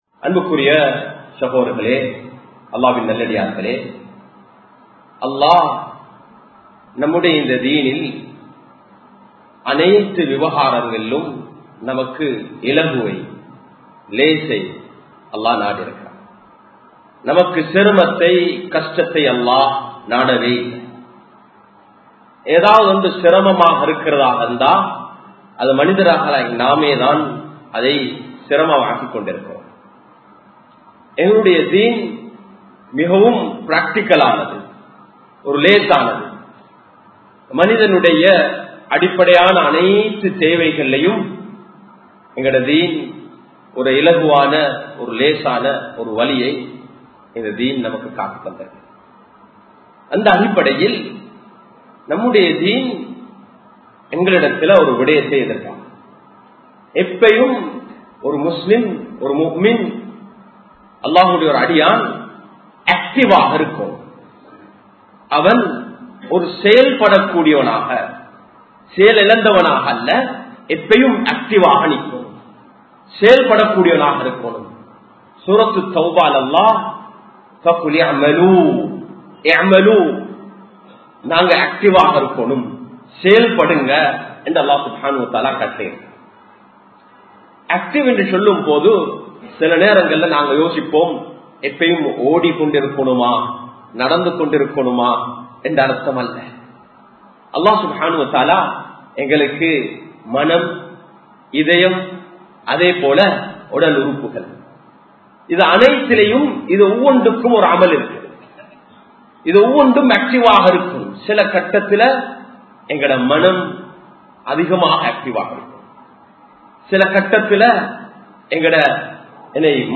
அல்லாஹ் ஏற்றுக்கொள்ளும் அமல்கள் | Audio Bayans | All Ceylon Muslim Youth Community | Addalaichenai